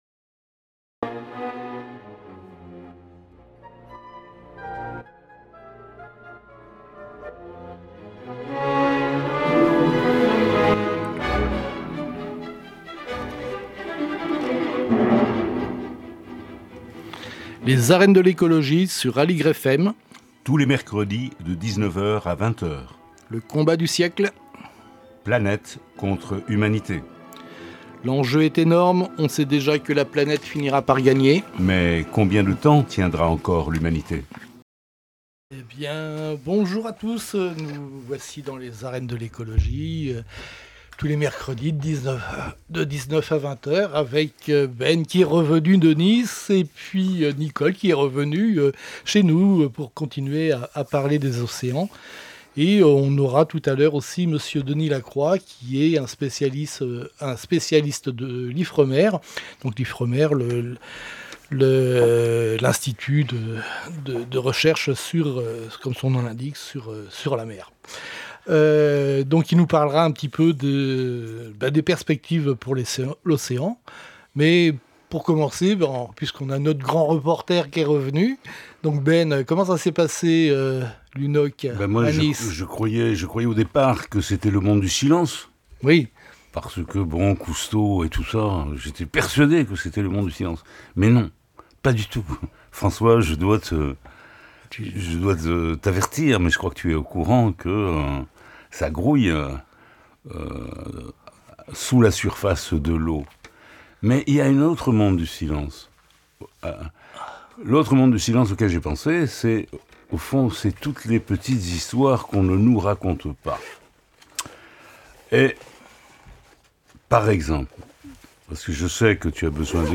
4 jours après la fermeture de l'UNOC 3 à Nice, nos invités tirent un bilan de cette 3è Conférence Internationale sur les Océans.